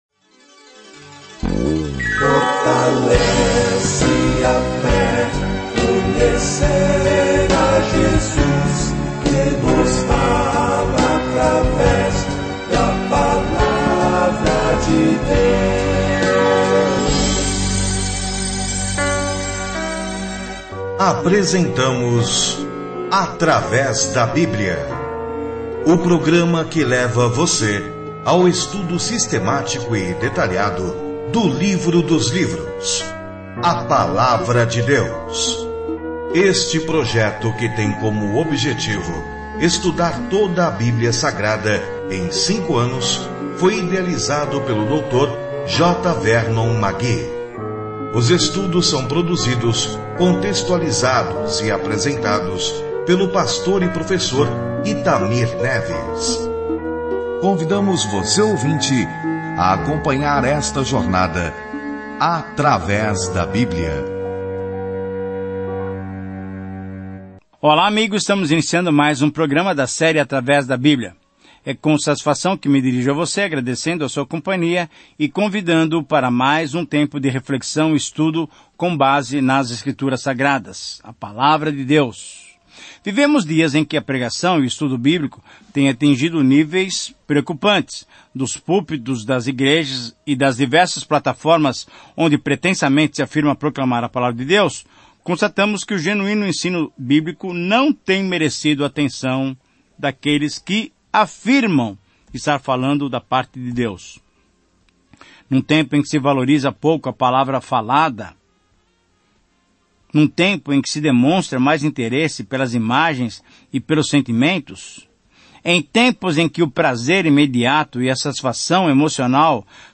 Viaje diariamente por Oséias enquanto ouve o estudo em áudio e lê versículos selecionados da palavra de Deus.